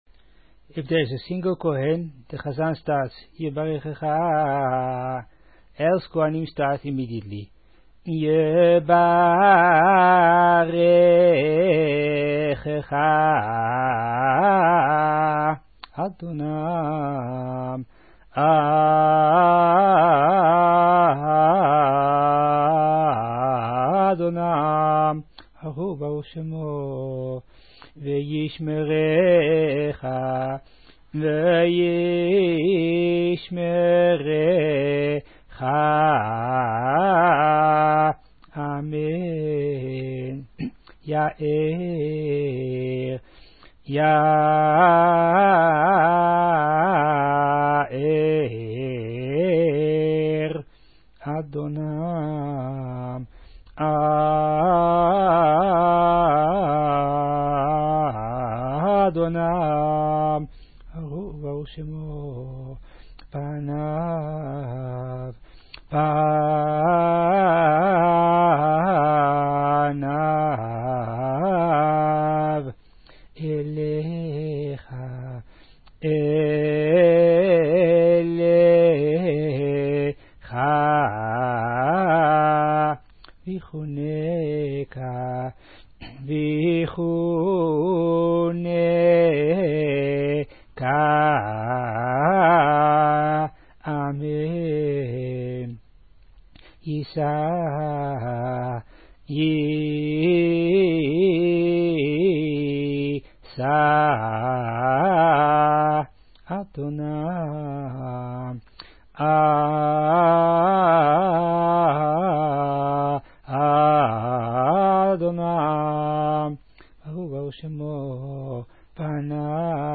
Festive melody, as this is the Shabbat this synagogue was inaugurated in 1995, see Shabbat Nachamu.